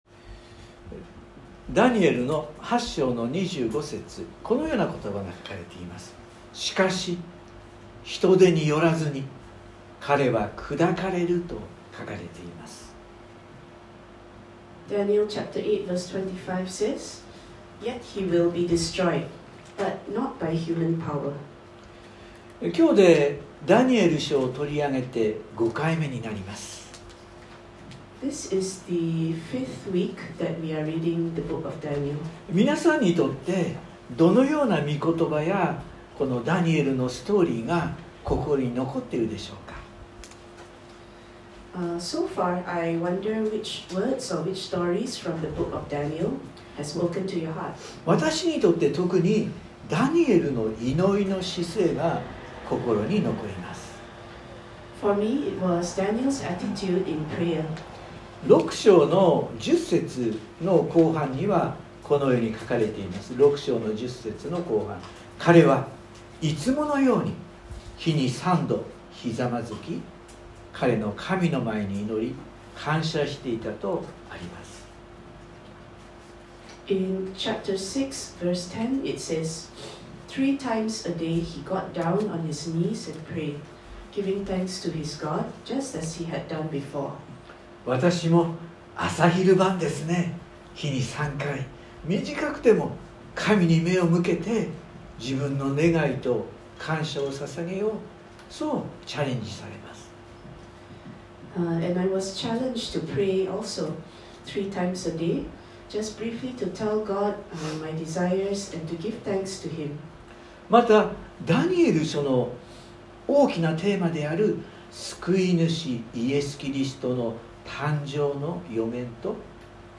↓メッセージが聞けます。（日曜礼拝録音）【iPhoneで聞けない方はiOSのアップデートをして下さい】今日でダニエル書を取り上げて、5回目になります。